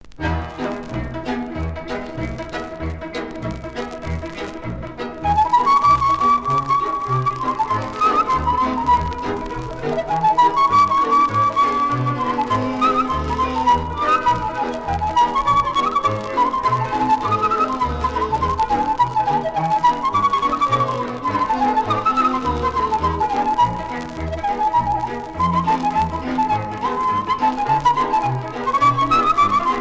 danse : hora (Roumanie)
Pièce musicale éditée